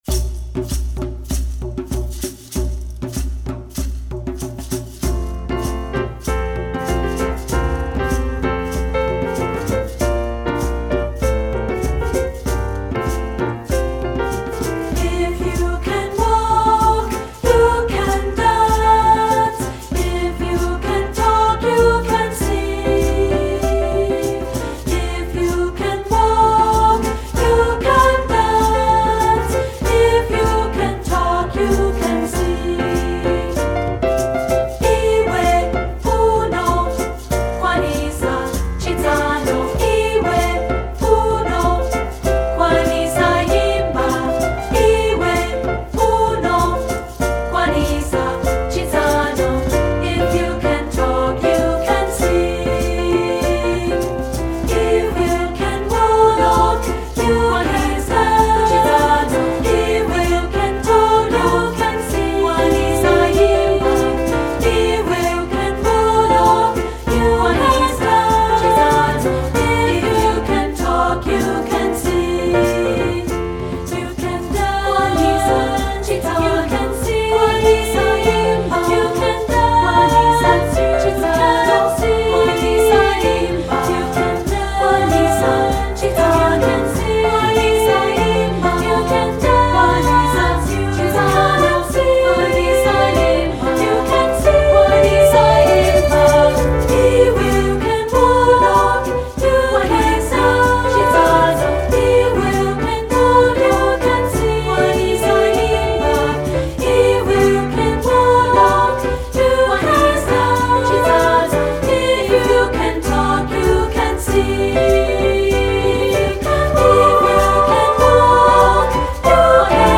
4th Grade Concert